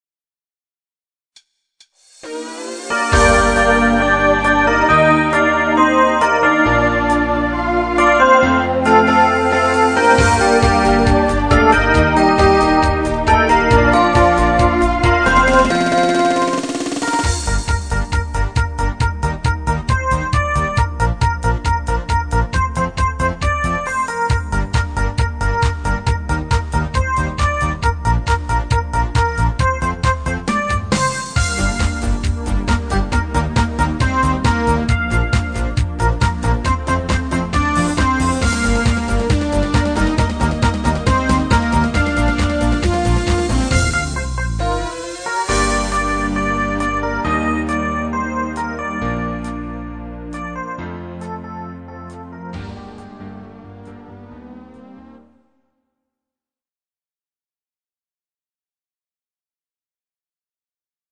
Genre(s): Deutschpop  Partyhits  |  Rhythmus-Style: Discofox
Dauer: 3:28 Minuten  | Tonart: Gm  | Produktionsjahr: 2022